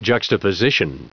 Prononciation du mot juxtaposition en anglais (fichier audio)
Prononciation du mot : juxtaposition